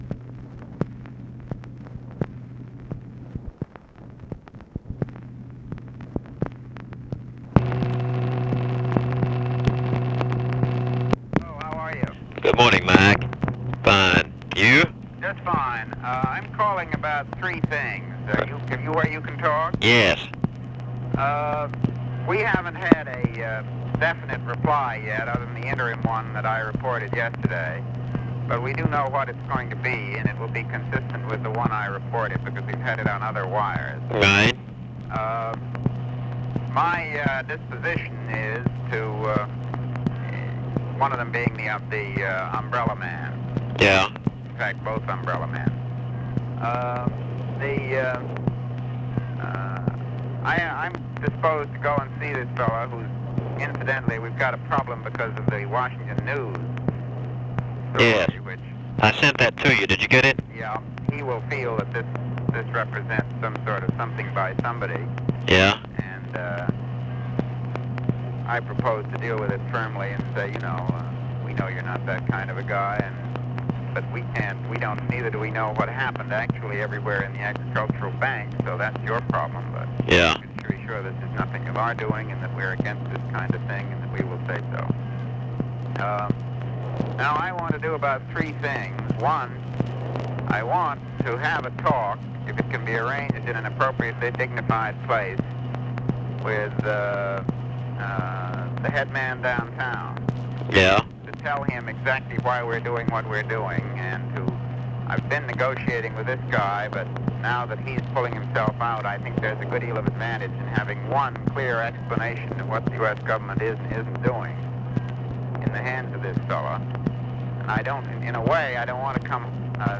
Conversation with MCGEORGE BUNDY, May 25, 1965
Secret White House Tapes